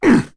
Kaulah-Vox_Attack4.wav